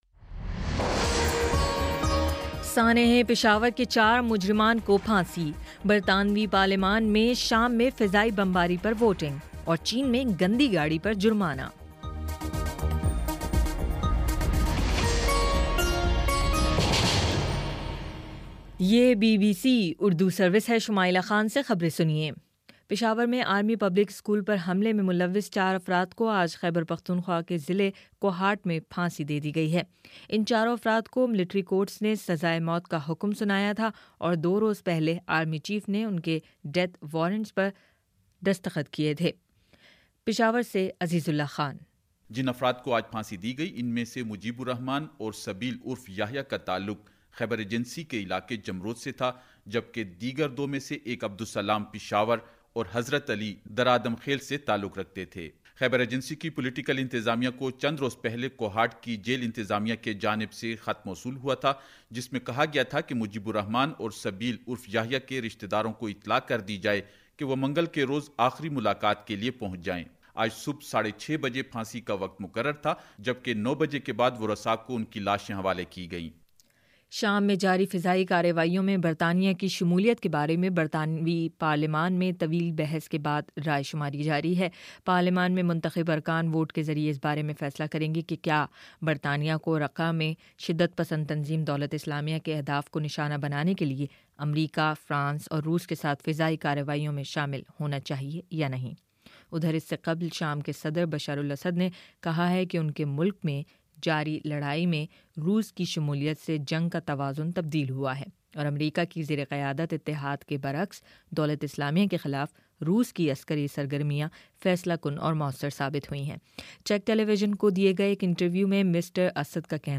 دسمبر 02 : شام چھ بجے کا نیوز بُلیٹن